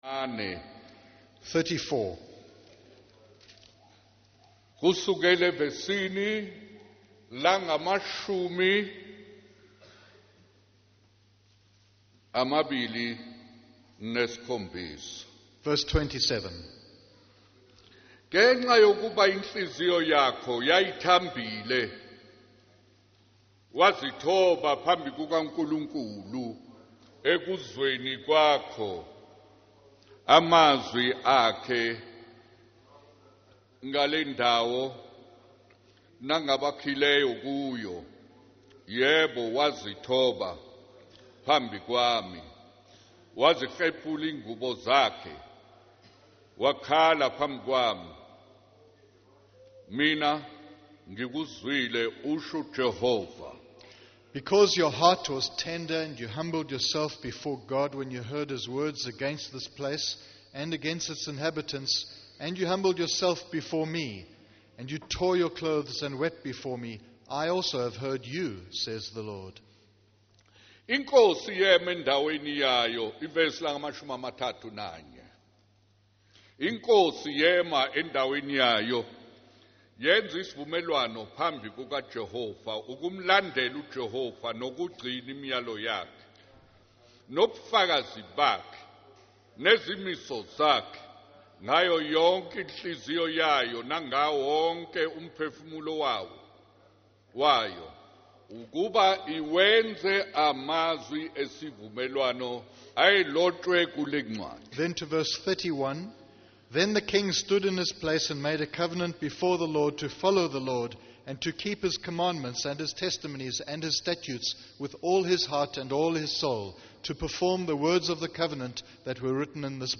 In this sermon, the preacher discusses the story of Gideon from the Bible.